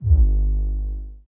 Bass Ramp up.wav